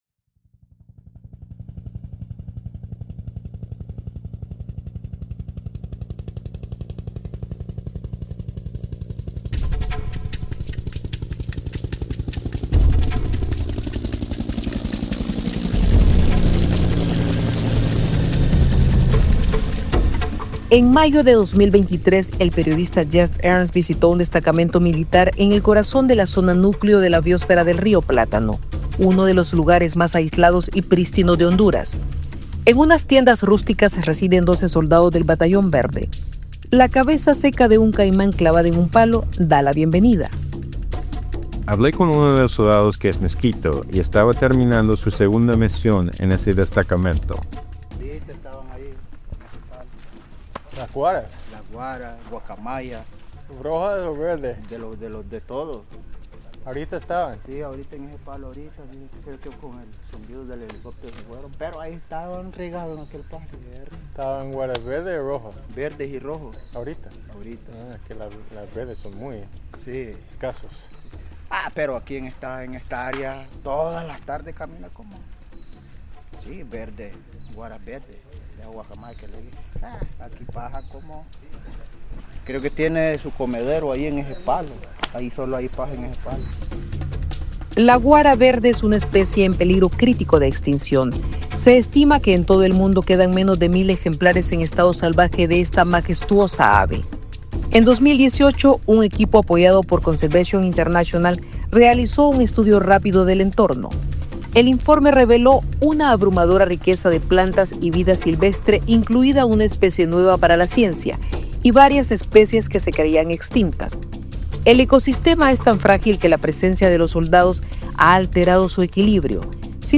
Entrevistas con expertos y pueblos indígenas analizarán las medidas que se están tomando actualmente, como la creación de un Batallón Verde por parte de la administración de la presidenta Xiomara Castro, y explorarán aquellas que podrían tomarse para evitar que las proyecciones se hagan realidad.